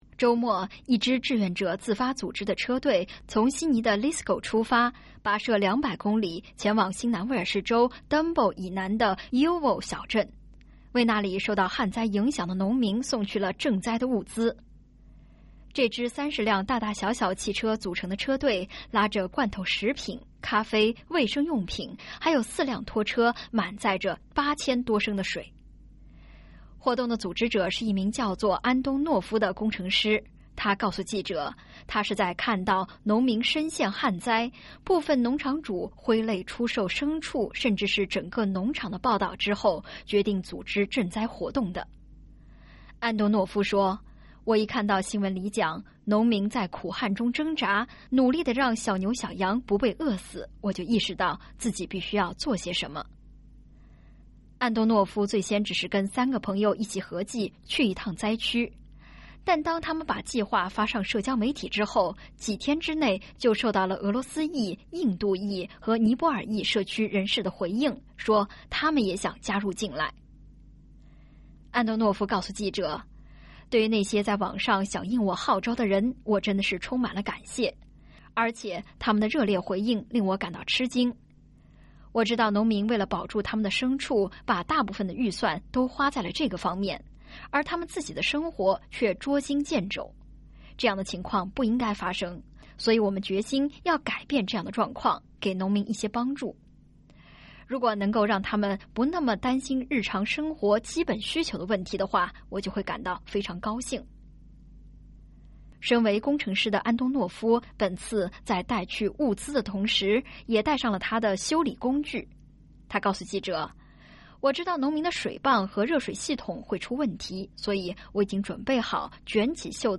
SBS News